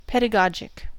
Ääntäminen
US : IPA : /ˌpɛ.dəˈɡɑːd.ʒɪk/ RP : IPA : /ˌpɛdəˈɡɒdʒɪk/